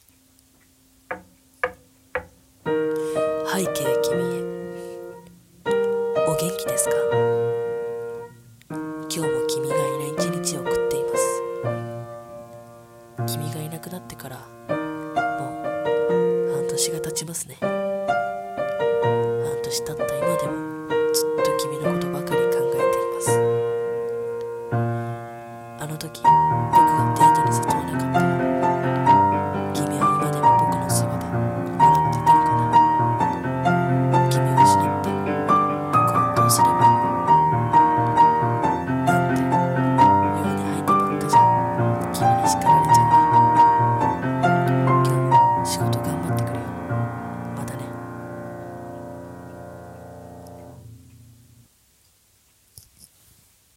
【声劇】 ー君へー(1人用)